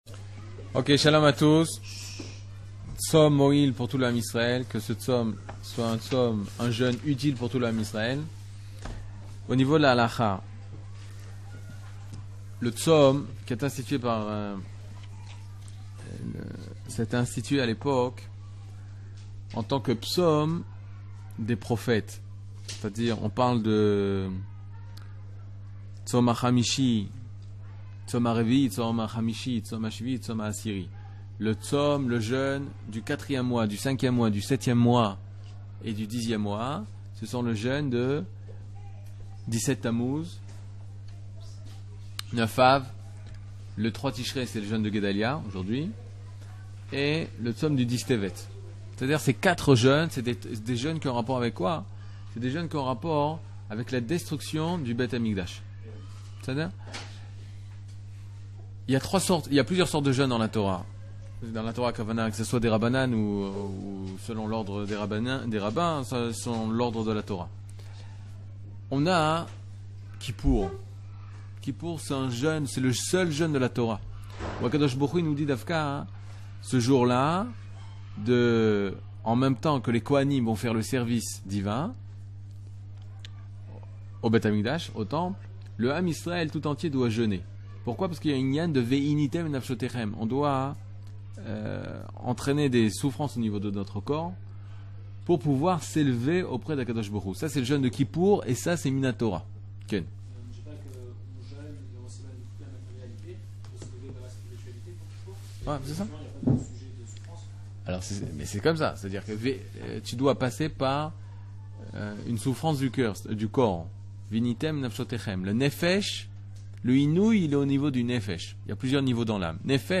Catégorie Le jeûne de Guedalia 00:26:56 Le jeûne de Guedalia cours du 28 septembre 2022 26MIN Télécharger AUDIO MP3 (24.65 Mo) Télécharger VIDEO MP4 (47.34 Mo) TAGS : Mini-cours Voir aussi ?